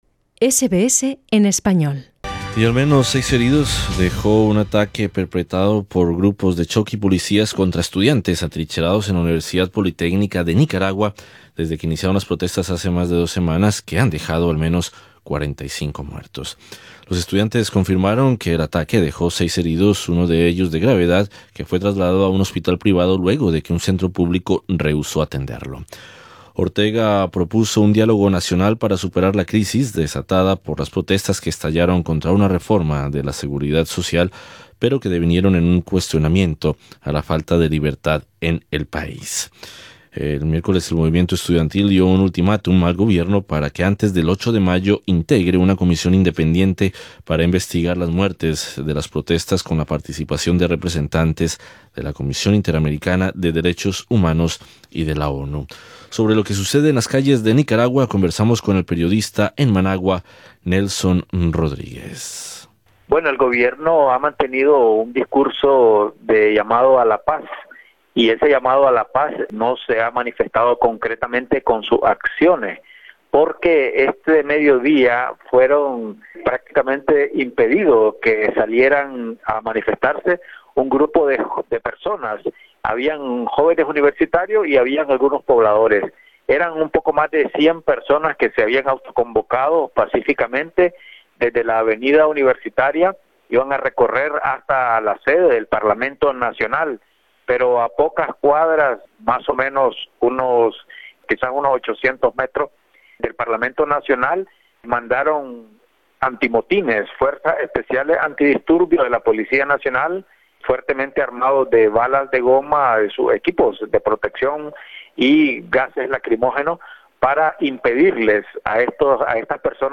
conversamos con el periodista en Managua